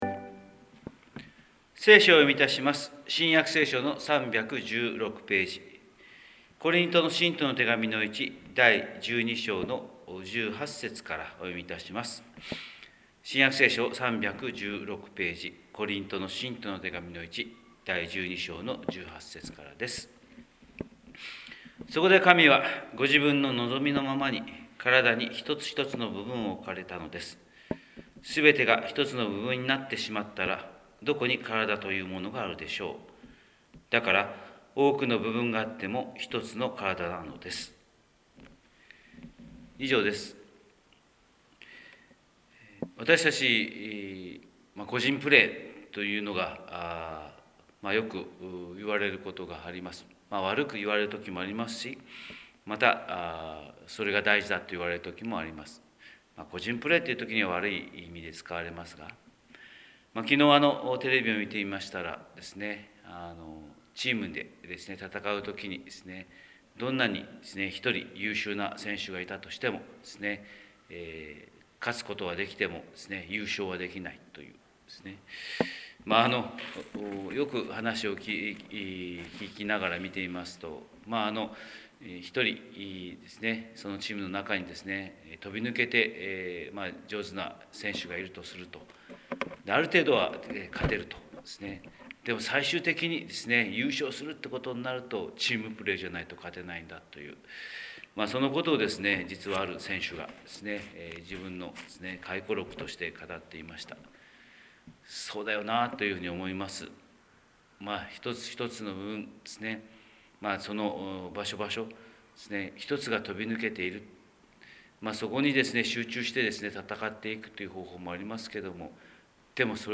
神様の色鉛筆（音声説教）: 広島教会朝礼拝250221
広島教会朝礼拝250221「１チームで」